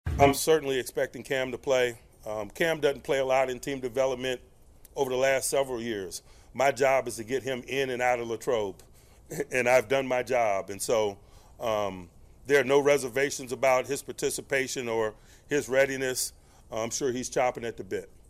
At his weekly news conference, Tomlin said Heyward will play, and his lack of work through training camp will not be a problem.